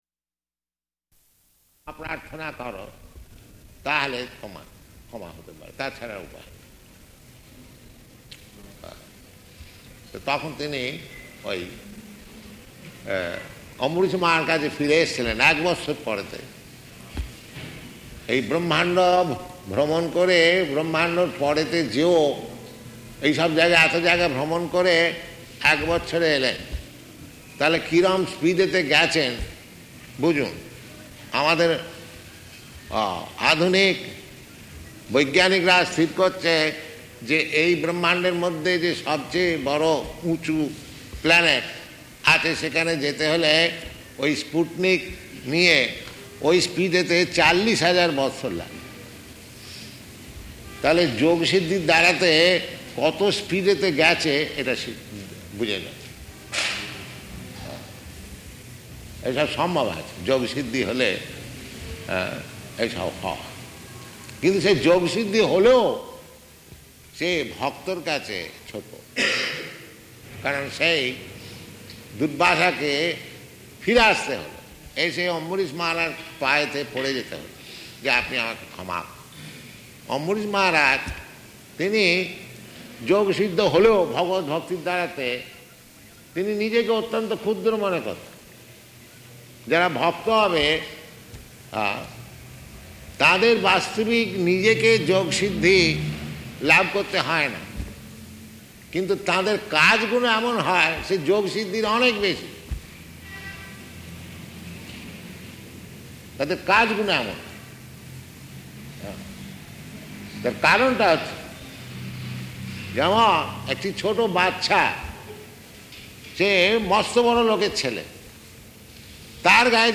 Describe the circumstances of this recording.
Location: Calcutta